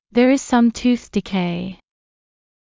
ｾﾞｱ ｲｽﾞ ｻﾑ ﾄｩｰｽ ﾃﾞｨｹｲ